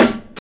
Snare (37).wav